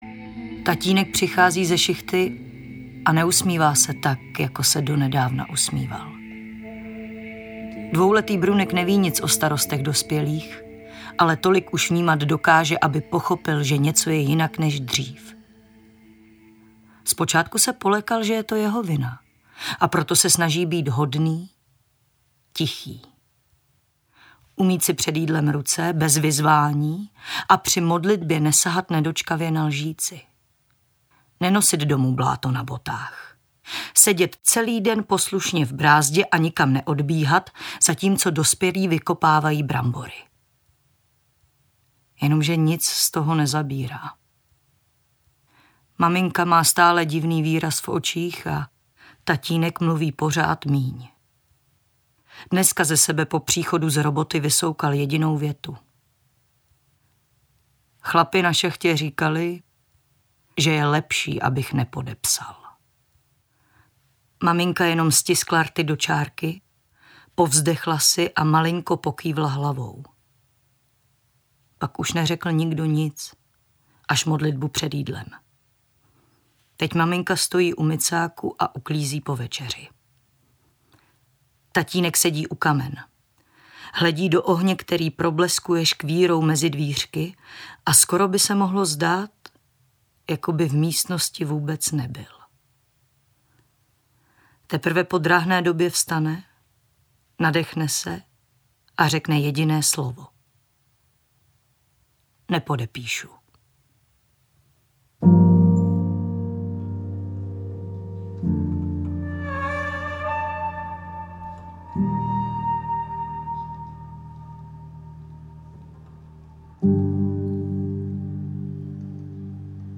Obálka audioknihy Životice